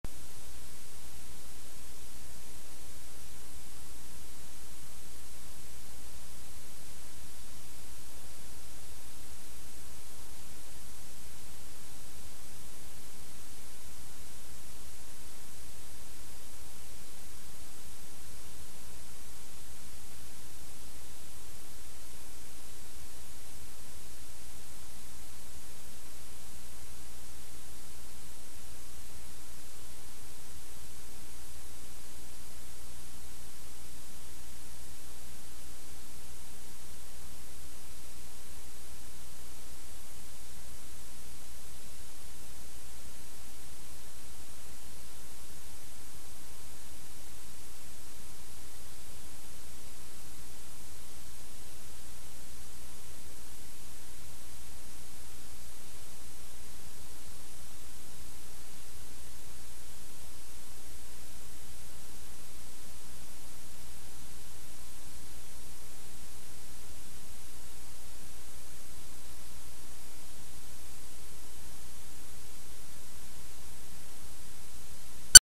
Song: [FREE VIDEO] REAL VHS Background Noisy Audio (VHS NOISE BACKGROUND) FREE